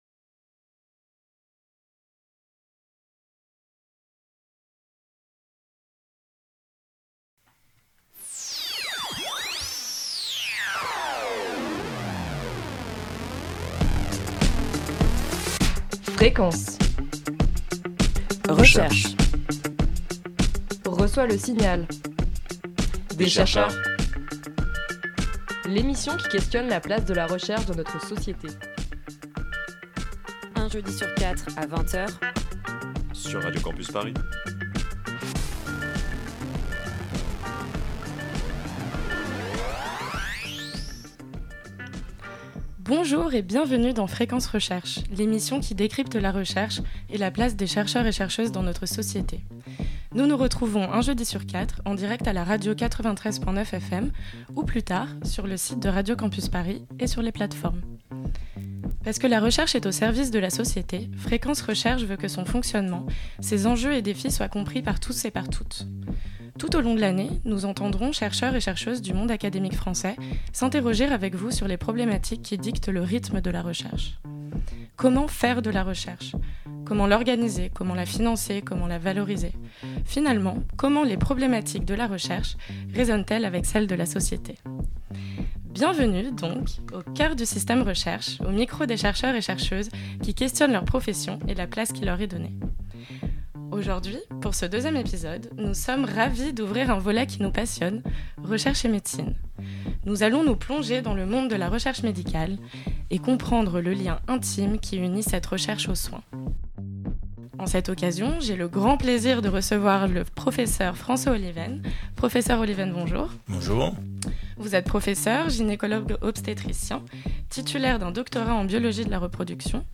Pour ce nouvel épisode, deux médecins-chercheurs vous plongent dans le monde de la recherche médicale. Avec pédagogie et humour, ils ont passé au peigne fin la façon dont s'articule la double casquette de médecin et de chercheur.euses.
Entretien